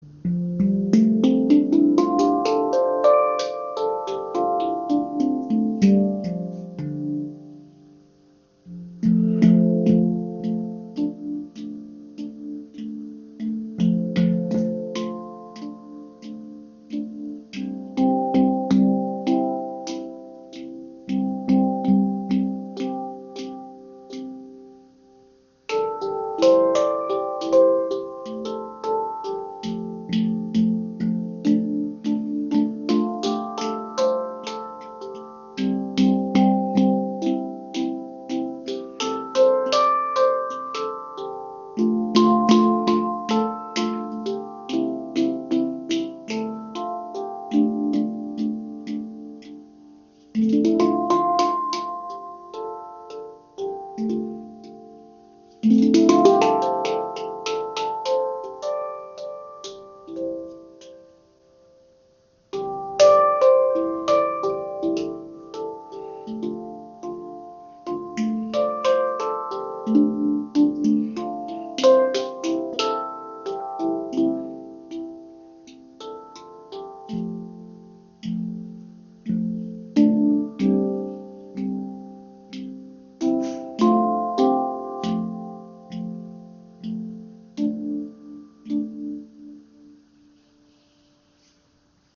D Amara Handpan – Fließender, lichtvoller Klang für Meditation & Improvisation • Raven Spirit
Die D Amara Handpan schafft mit ihrem offenen, klaren Klangraum eine beruhigende Atmosphäre.
Klangbeispiel
Die ungarische Manufaktur MAG Instruments steht für hochwertige Handpans mit edler Oberfläche, warmem Klang und langem Sustain.
D Amara – Fließend, lichtvoll und weit Die D-Amara-Stimmung erzeugt eine sanfte, offene Klangwelt mit schwebender Leichtigkeit und melodischer Klarheit.